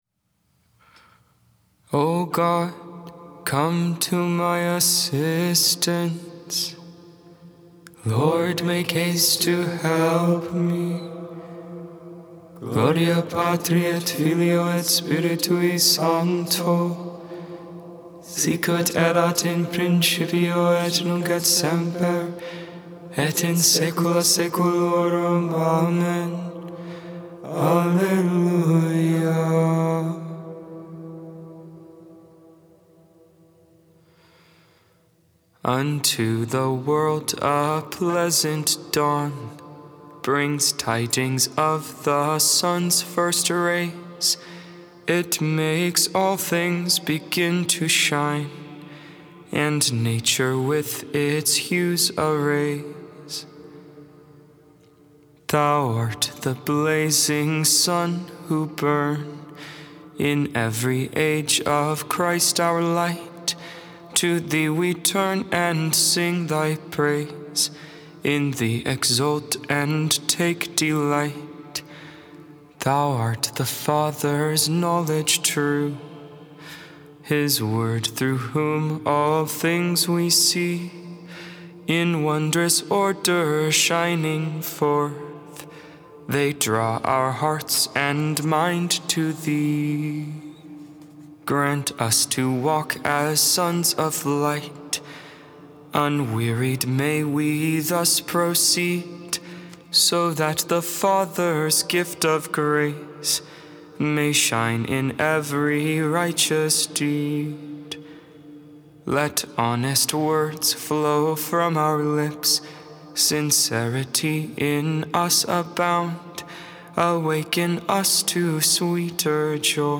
8.23.22 Lauds, Tuesday Morning Prayer